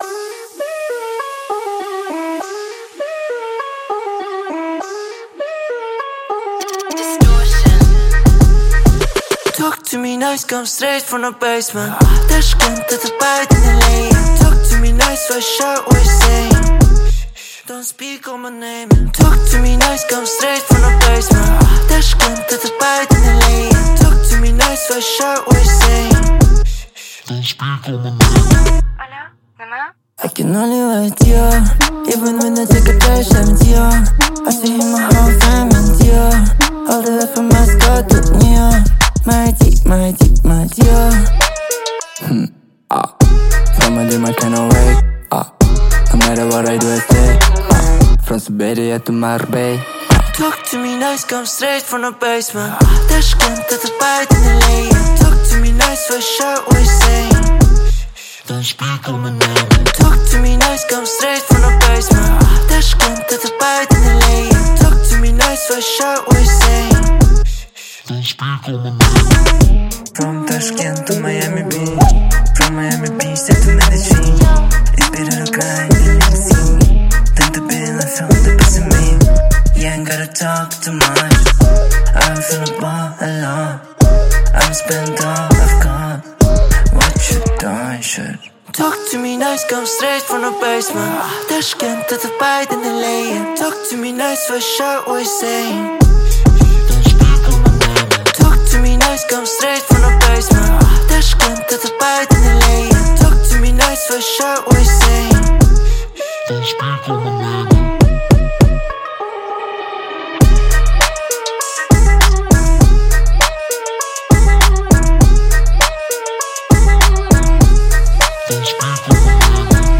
آهنگ شاد
آهنگ پاپ خارجی
آهنگ رپ
rap music